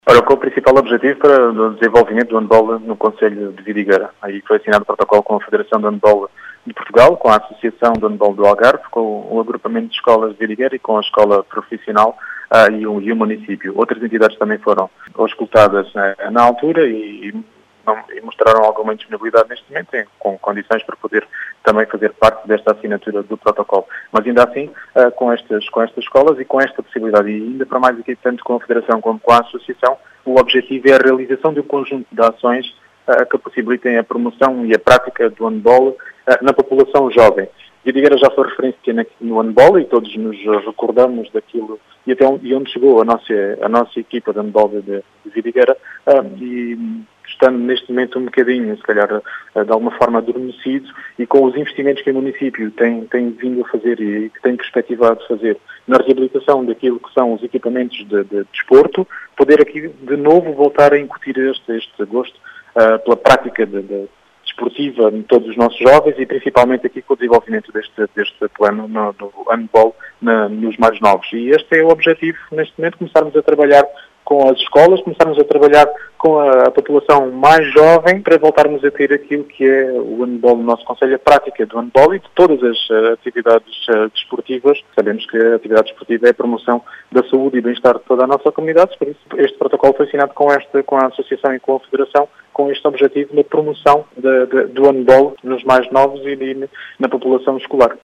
As explicações são de Rui Raposo, presidente da Câmara de Vidigueira, que quer “incutir” novamente o gosto por esta modalidade no concelho.